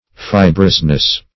Fibrous \Fi"brous\ (f[imac]"br[u^]s), a. [Cf. F. fibreux.]